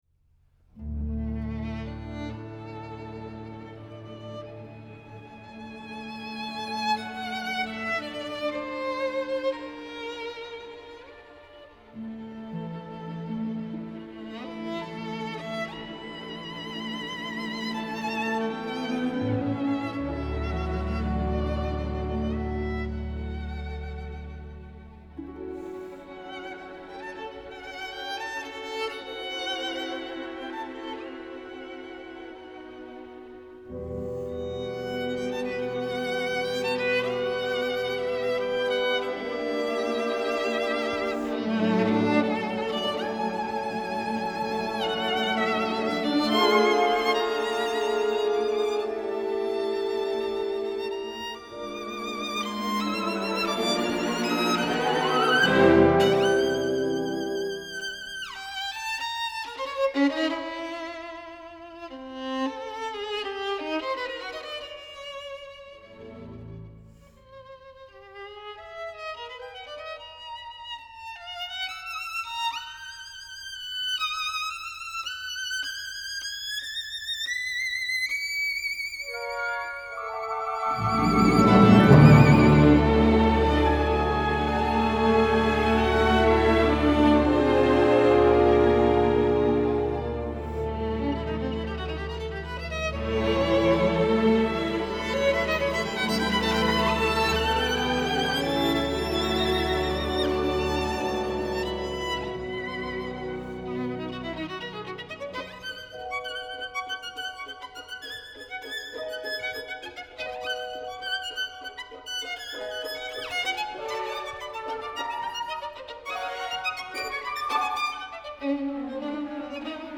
Recorded at Odense Koncerthus, June 2017